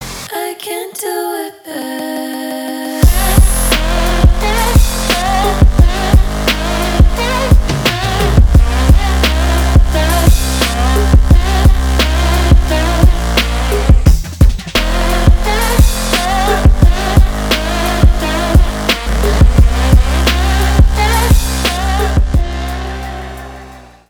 • Качество: 320, Stereo
атмосферные
мощные басы
Trap
красивый женский голос
future bass
погружающие